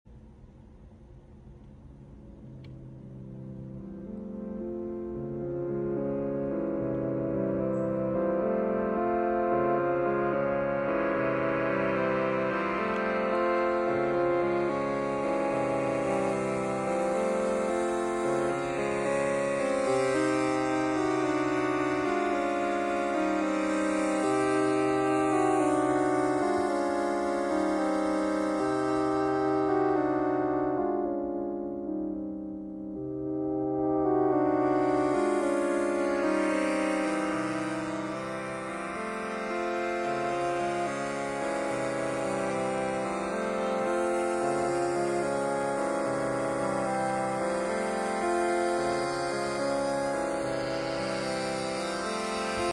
ANOTHER sweet, jammin’ synthesizer sequence sound effects free download